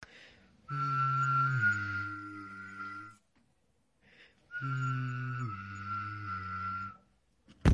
哨子
描述：声音是用Zoom H6录制的。有人吹口哨
标签： 吹口哨 OWI
声道立体声